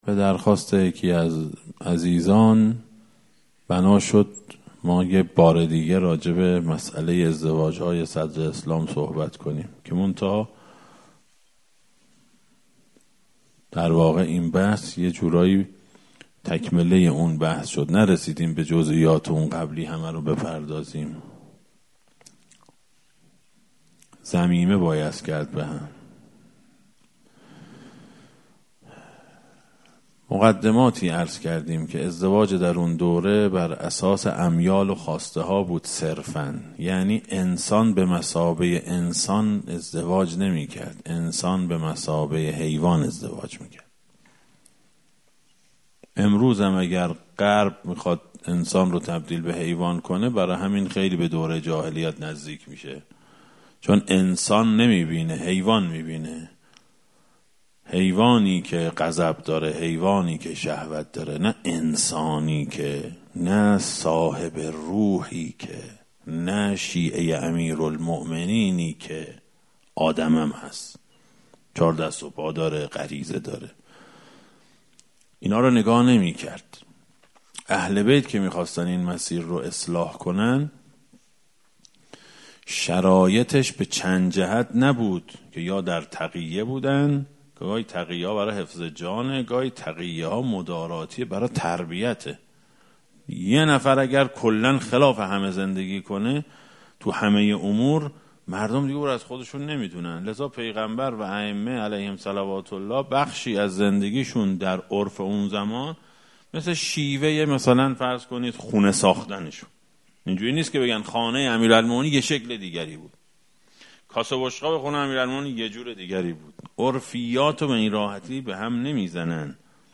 در هیئت محترم بضعة الرسول سلام الله علیهما